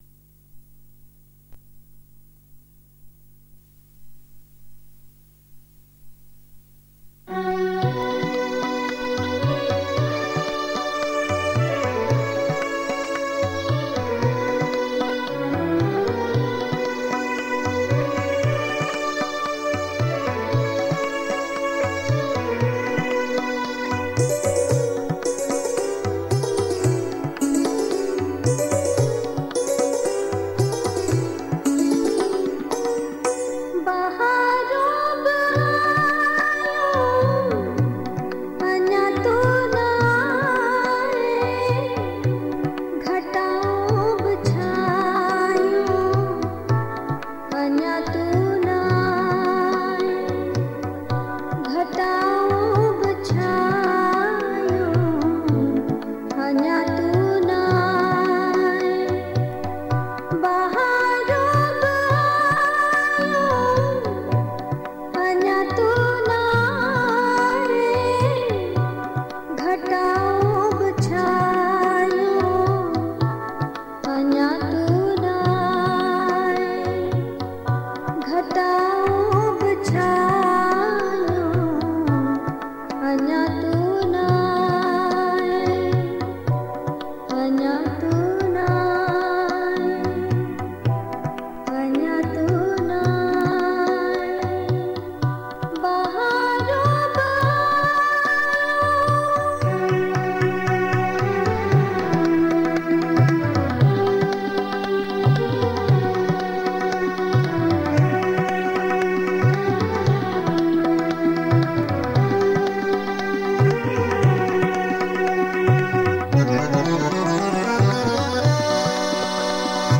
Sindhi Geet and Kalam